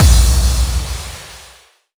VEC3 FX Reverbkicks 14.wav